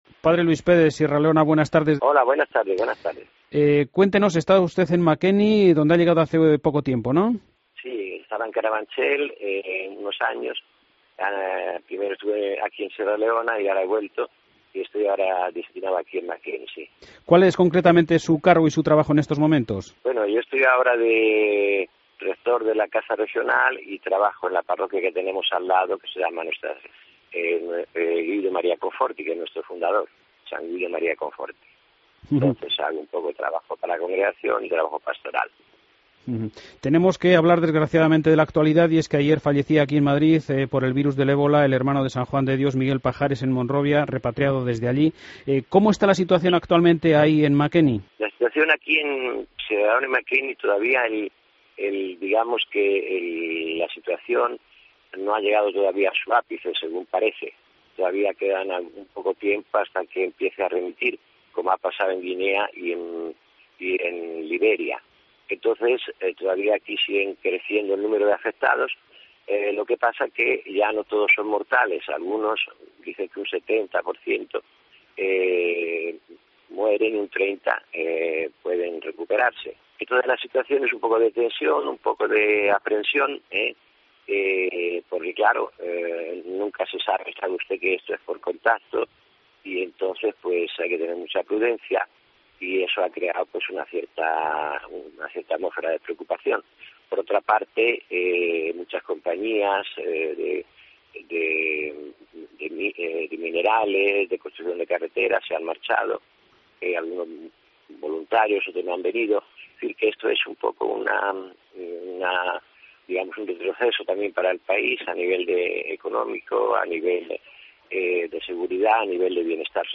AUDIO: Desde Makeni, en Sierra Leona.